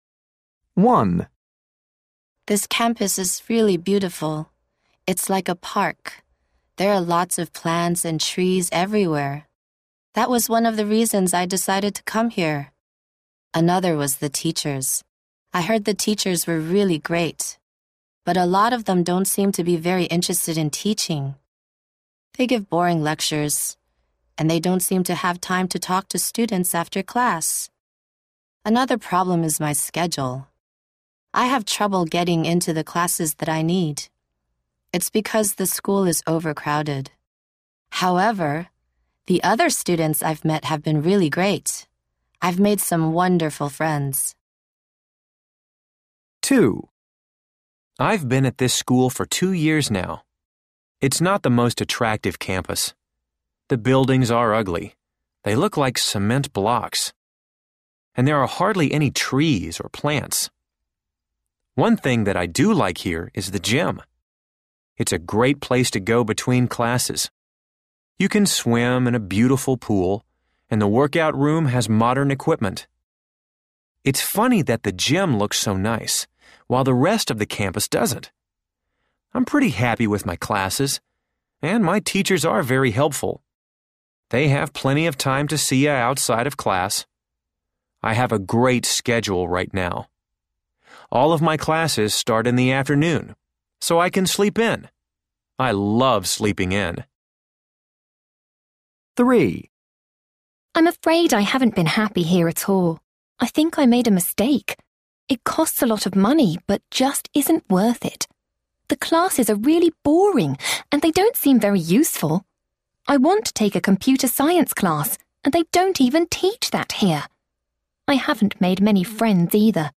A. People are talking about their schools.